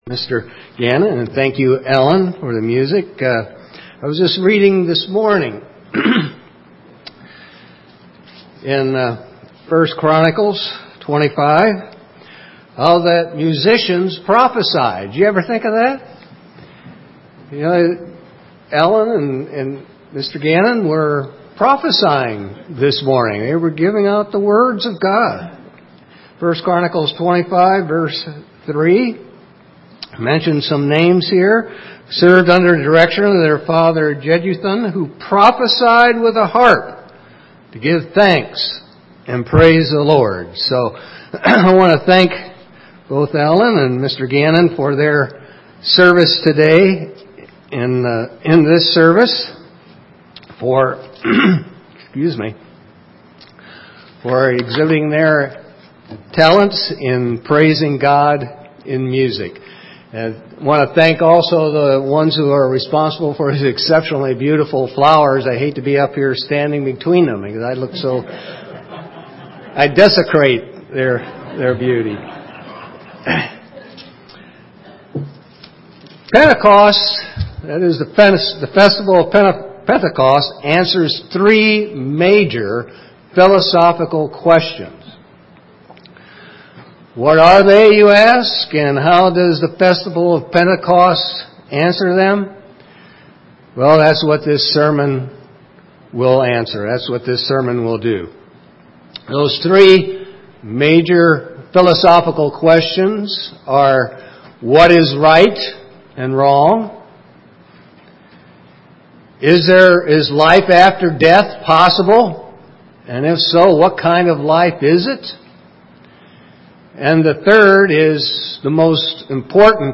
This split-sermon was given on the Feast of Pentecost.